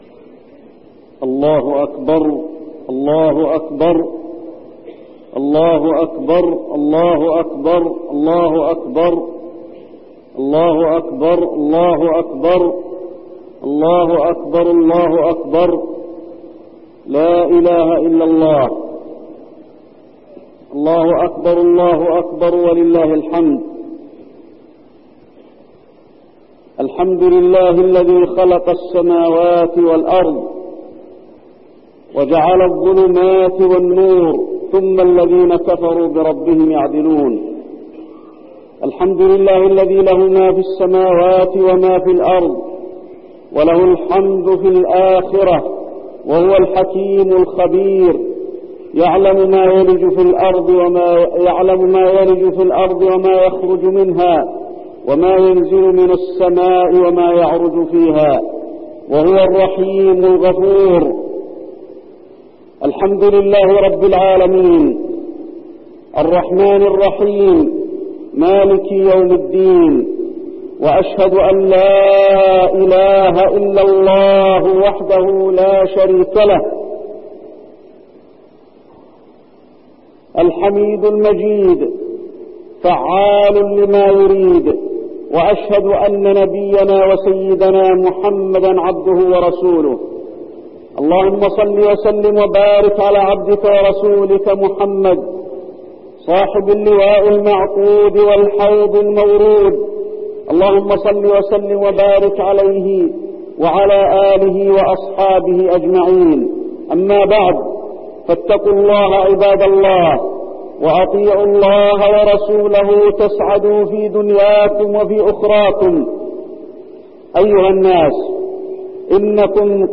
خطبة الاستسقاء - المدينة- الشيخ علي الحذيفي - الموقع الرسمي لرئاسة الشؤون الدينية بالمسجد النبوي والمسجد الحرام
المكان: المسجد النبوي